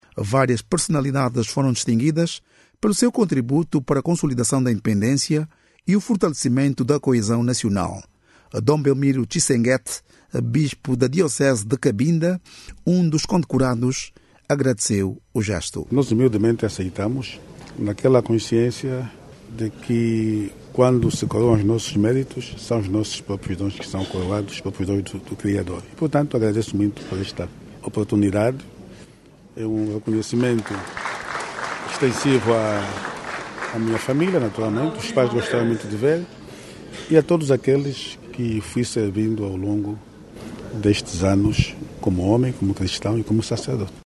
Prossegue hoje, sábado, 25, a sétima cerimónia de condecoração em alusão as comemorações dos 50 anos de Independência Nacional. O evento, que teve início na sexta-feira(24), distingue personalidades e instituições que se destacaram ao longo das cinco décadas de soberania nacional. Saiba mais dados no áudio abaixo com o repórter